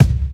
Royality free bass drum tuned to the C# note. Loudest frequency: 874Hz
• Raw Kick Drum Single Shot C# Key 241.wav
raw-kick-drum-single-shot-c-sharp-key-241-zrv.wav